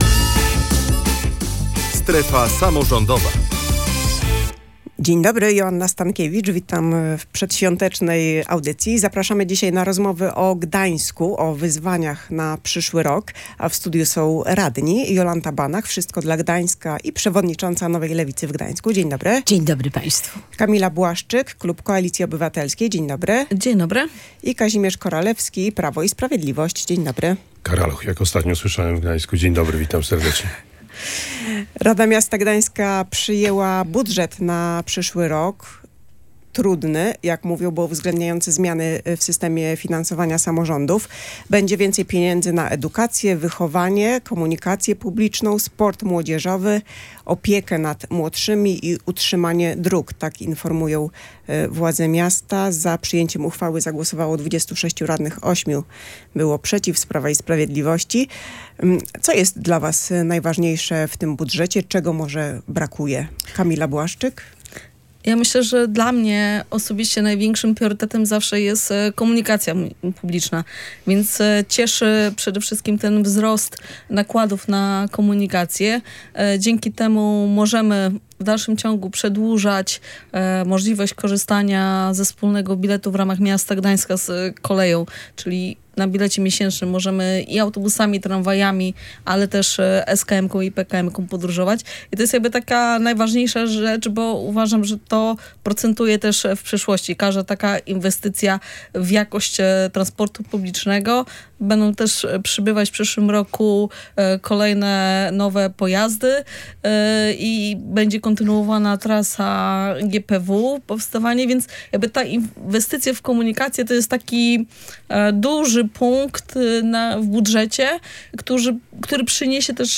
w audycji „Strefa Samorządowa”